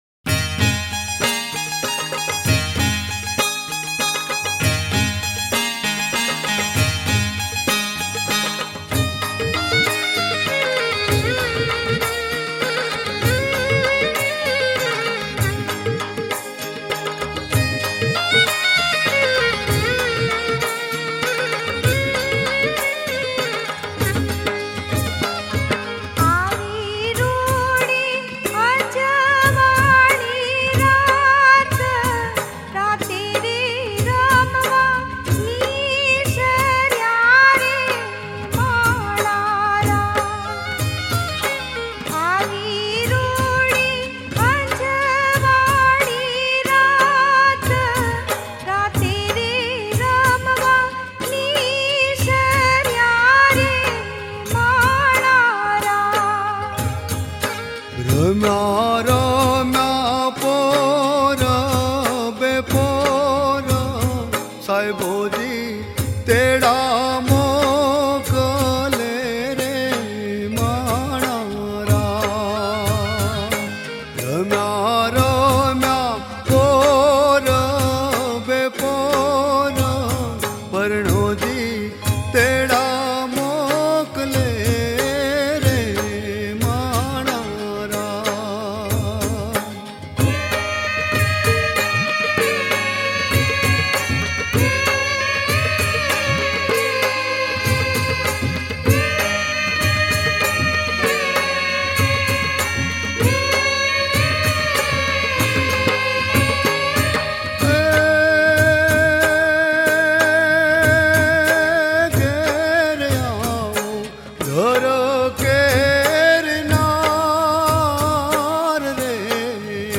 ગીત સંગીત ગરબા - Garba
ગુજરાતી લોકગીત
TRADITIONAL FOLK SONGS.